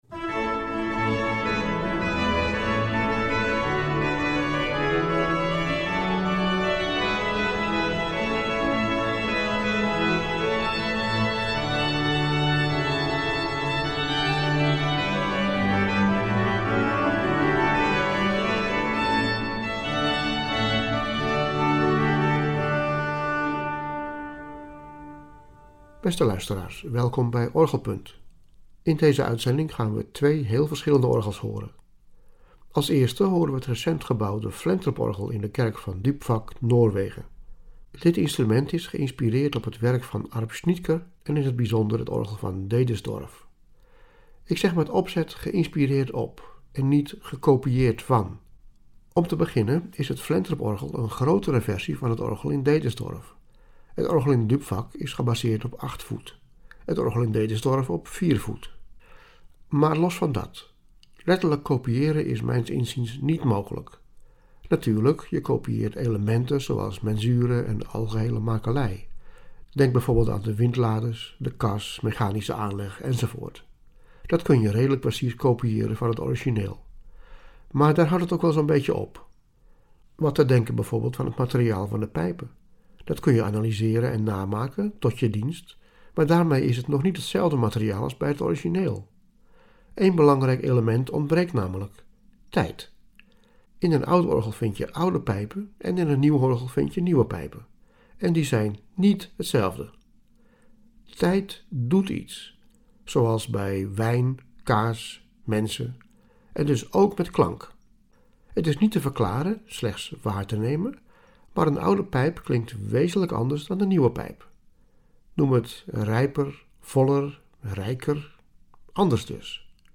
We gaan luisteren naar opnames van uiteenlopende orgels in Denemarken, Zweden en Noorwegen. Variërend van historische instrumenten tot nieuw gebouwde orgels.